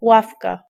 Ääntäminen
Synonyymit étal Ääntäminen France: IPA: [ɛ̃ bɑ̃] Tuntematon aksentti: IPA: /bɑ̃/ Haettu sana löytyi näillä lähdekielillä: ranska Käännös Ääninäyte 1. ławica {f} 2. ławka {f} Suku: m .